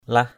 /lah/